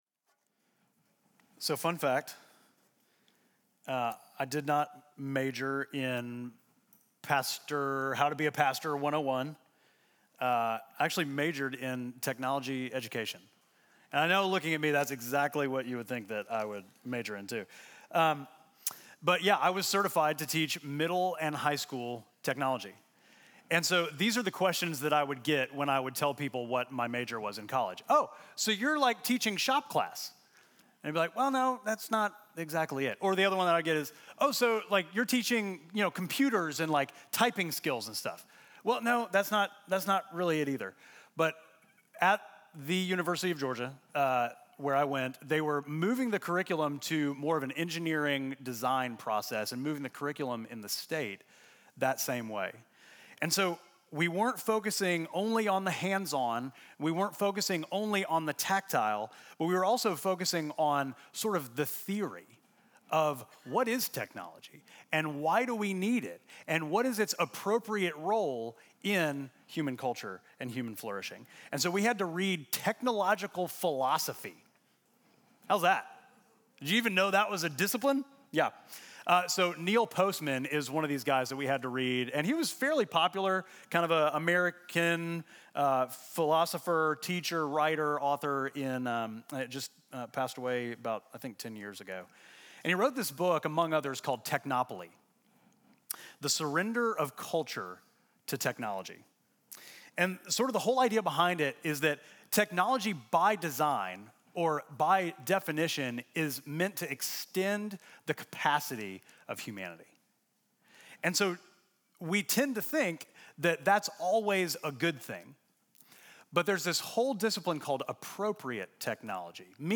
Midtown Fellowship Crieve Hall Sermons My Shepherd Jun 09 2024 | 00:28:33 1x 00:00 / 00:28:33 Subscribe Share Apple Podcasts Spotify Overcast RSS Feed Share Link Embed